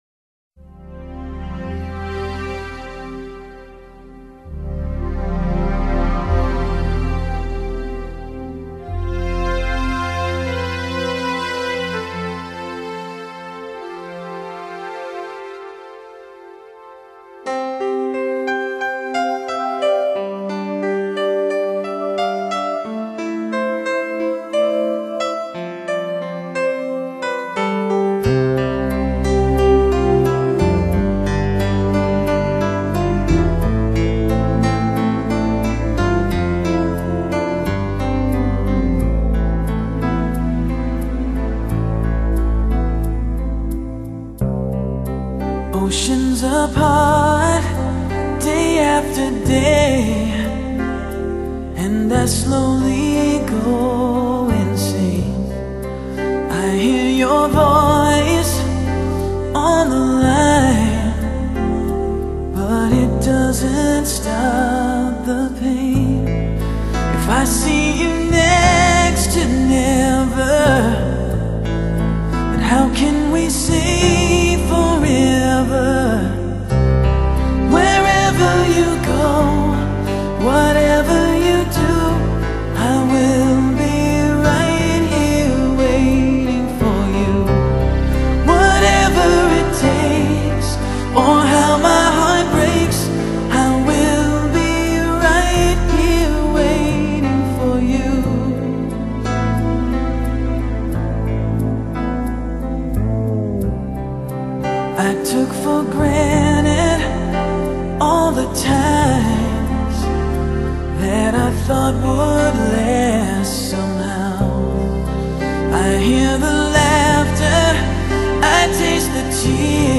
Genre: Pop, Soul, R&B, Ballad